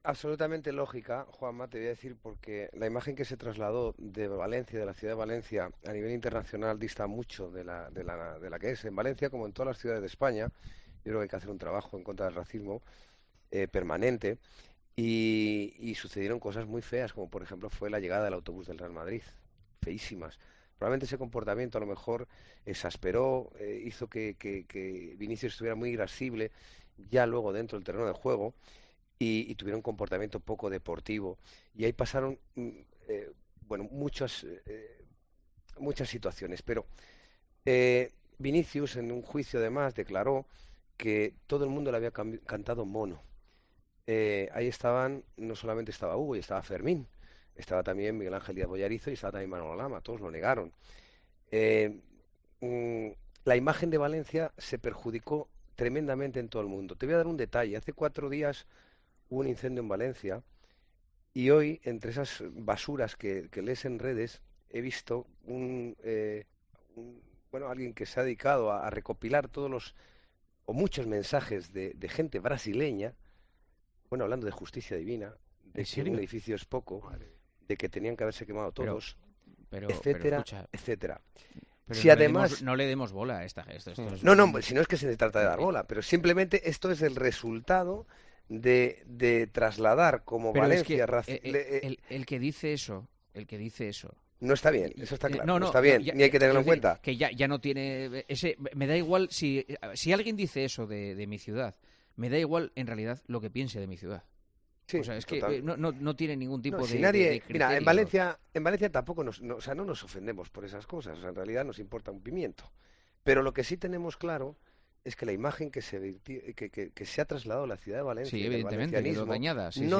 Santi Cañizares habló sobre esta circunstancia y puso su ejemplo en El Partidazo de COPE.